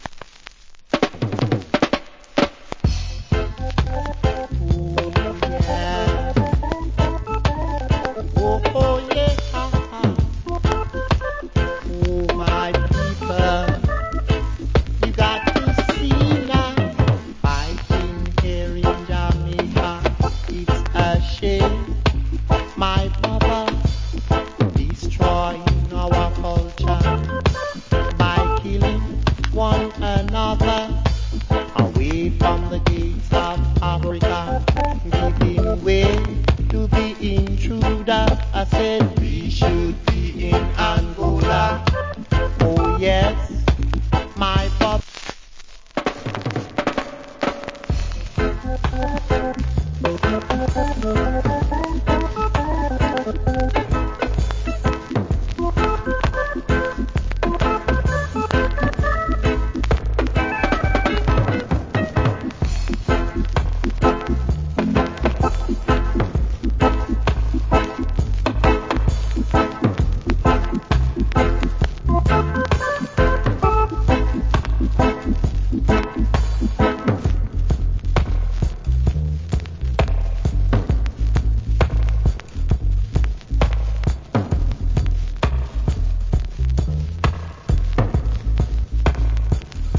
Roots Rock Vocal. / Good Dub.